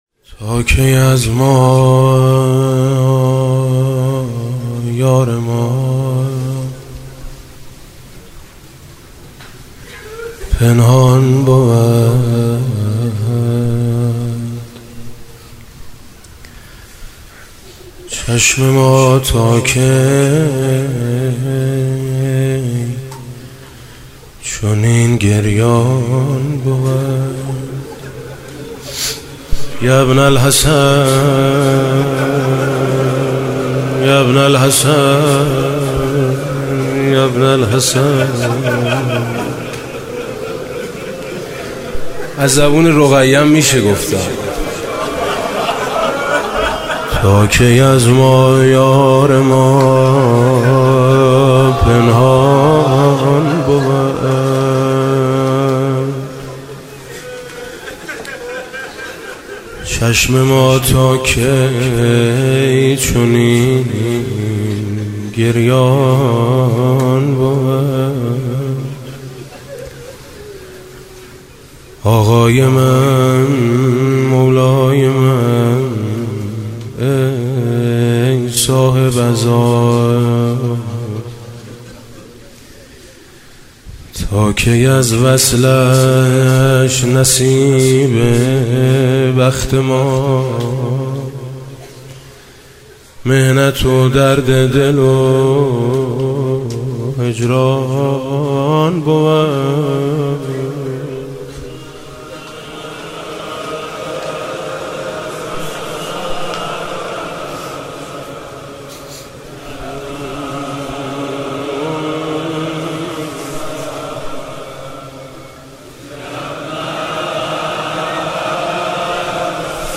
گلچین مداحی شب سوم محرم ۹۸ با نوای میثم مطیعی
فایل صوتی گلچین مداحی شب سوم محرم ۹۸ با نوای مداح اهل بیت(ع) حاج میثم مطیعی در اینجا قابل دریافت است.